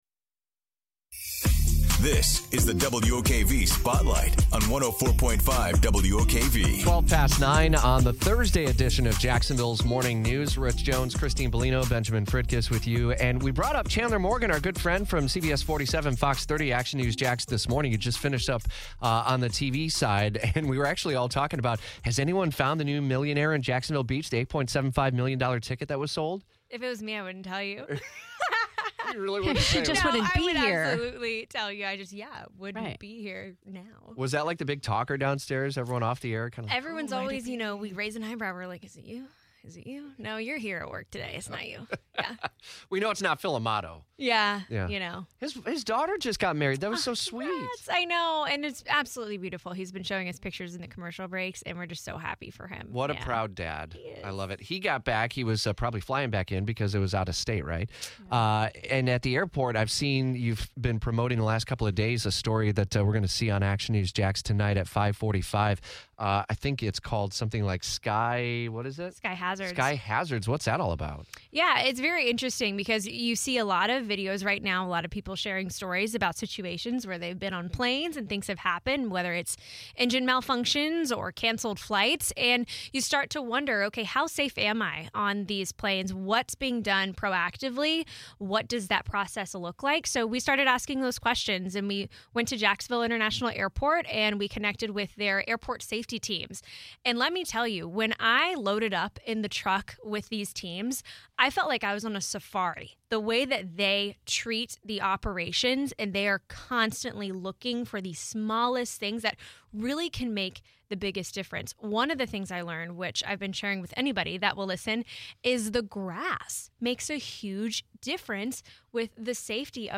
joined Jacksonville’s Morning News with a story about the